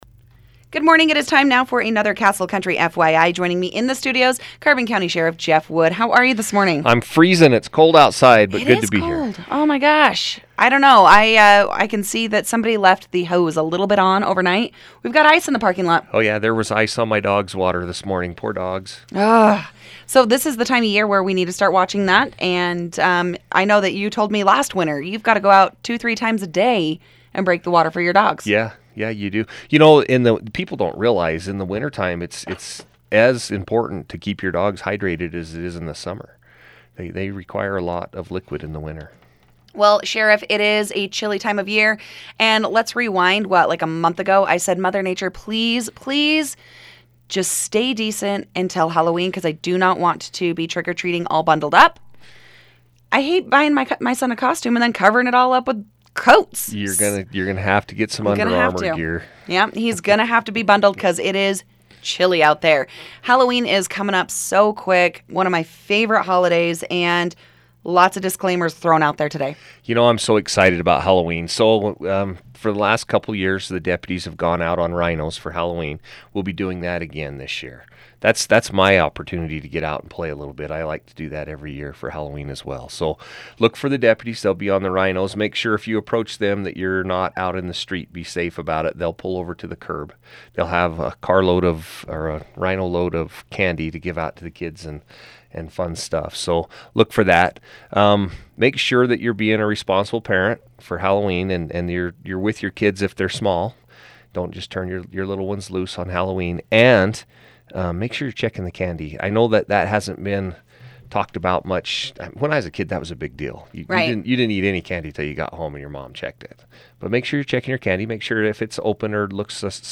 Carbon County Sheriff Jeff Wood talks Halloween Safety